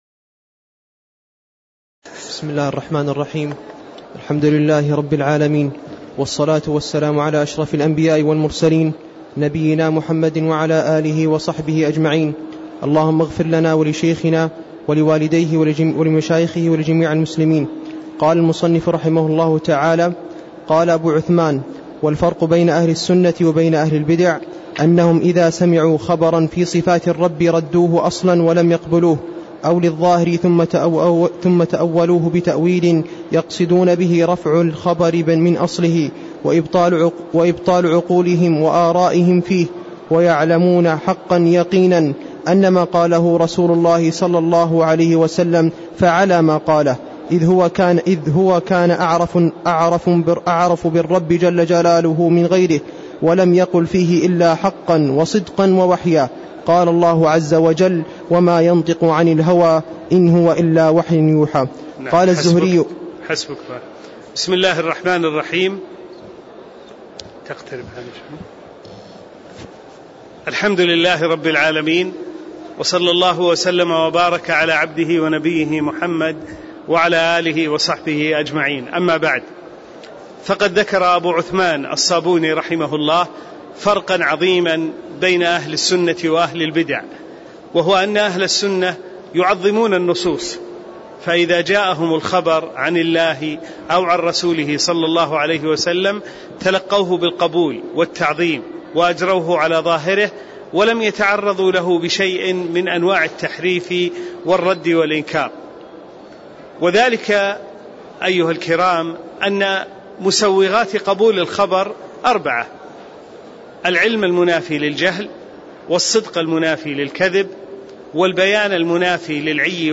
تاريخ النشر ٢٣ شعبان ١٤٣٦ هـ المكان: المسجد النبوي الشيخ